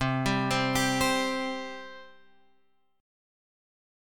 C 5th